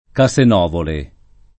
[ ka S en 0 vole ]